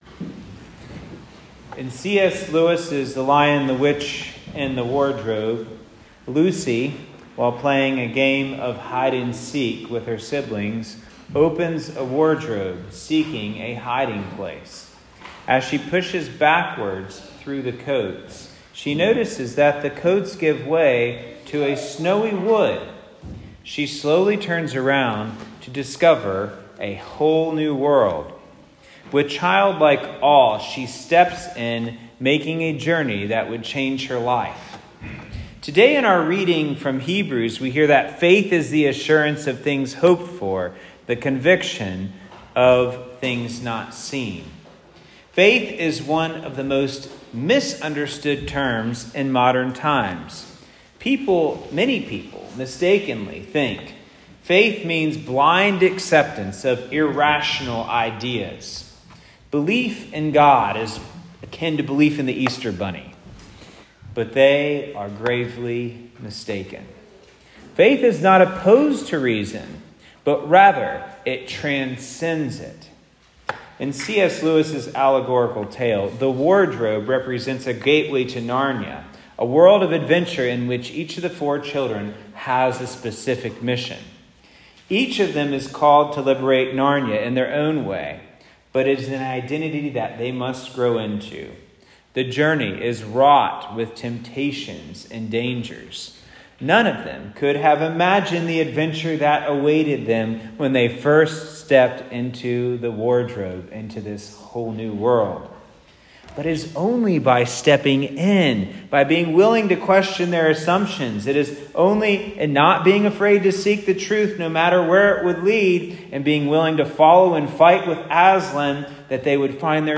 Listen to Father's Homily from Sunday